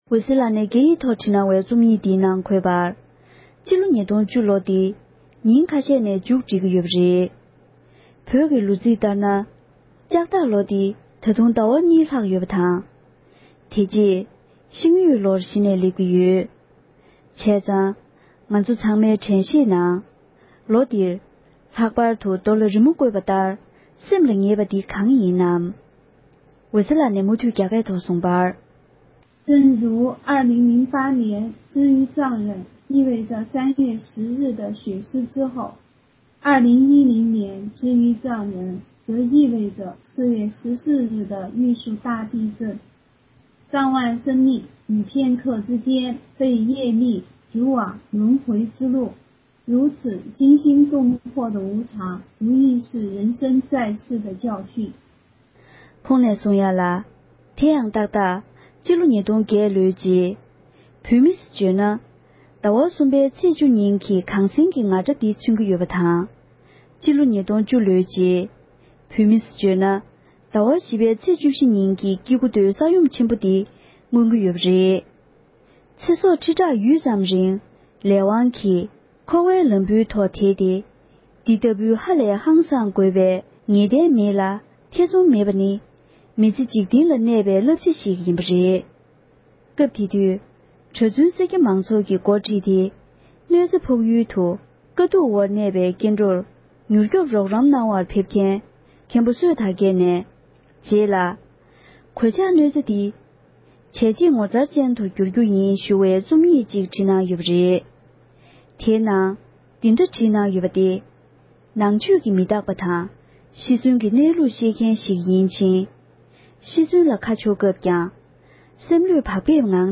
ཕབ་བསྒྱུར་དང་སྙན་སྒྲོན་ཞུས་པར་གསན་རོགས༎